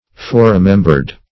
Search Result for " foreremembered" : The Collaborative International Dictionary of English v.0.48: Foreremembered \Fore`re*mem"bered\, a. Called to mind previously.